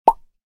snd_pop.ogg